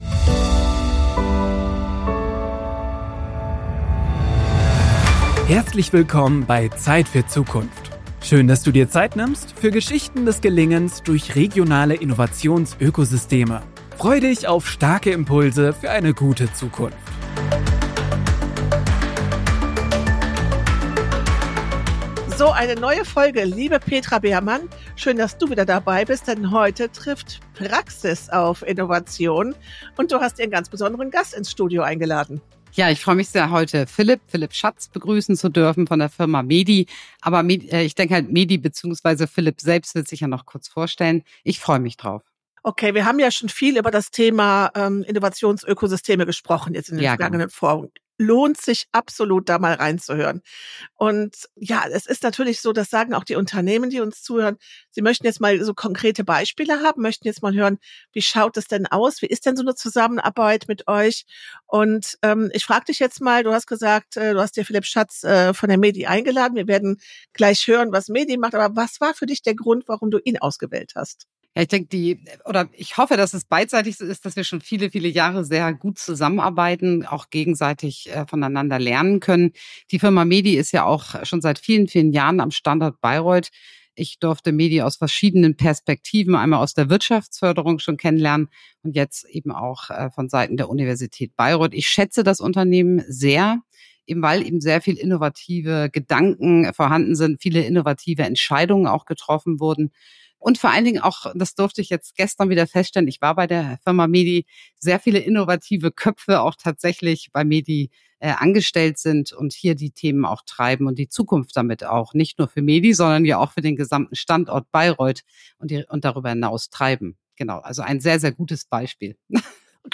als Gast im Studio